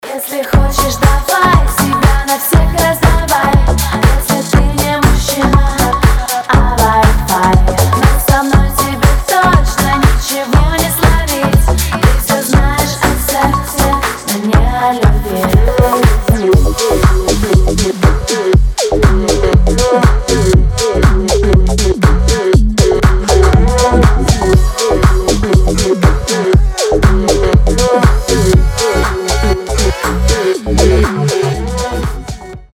• Качество: 320, Stereo
поп
женский вокал
dance
Electronic
club